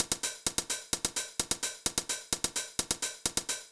INT Beat - Mix 10.wav